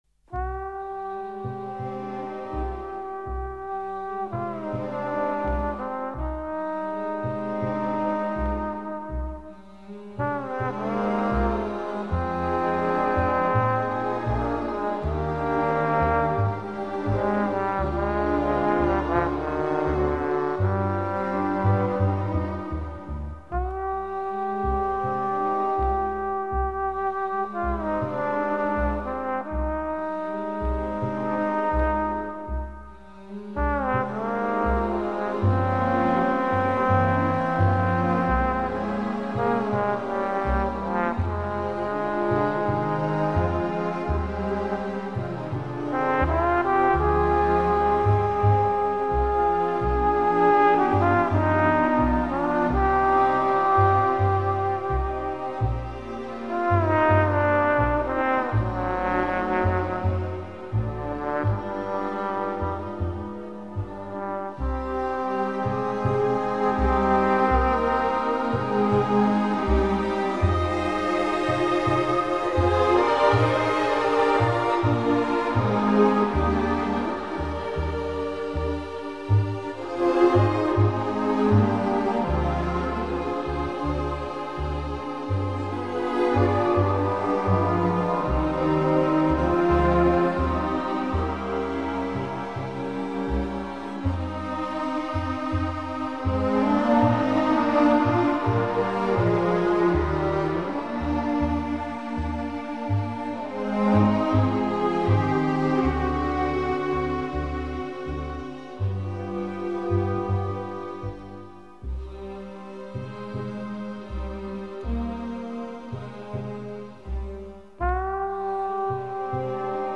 Astor Piazzolla - Oblivion (тромбон ).mp3